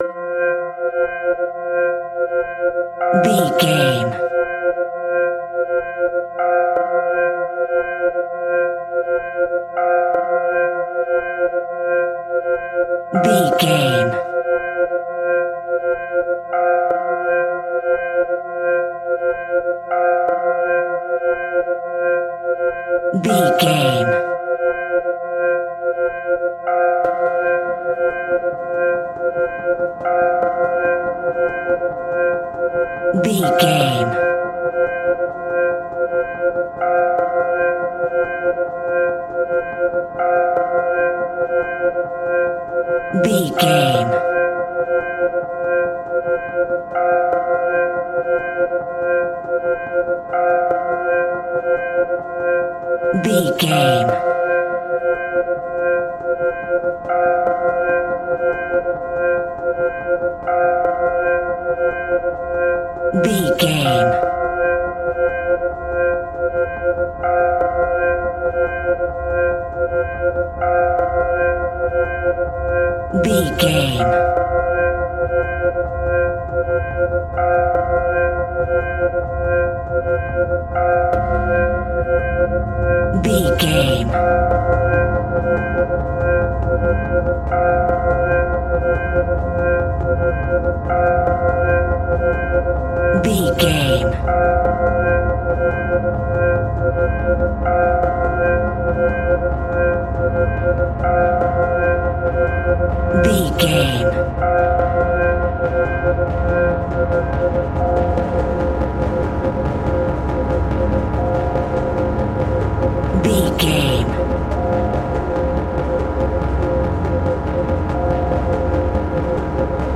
Graveyard Music.
In-crescendo
Atonal
scary
ominous
eerie
instrumentals
horror music
Horror Pads
horror piano
Horror Synths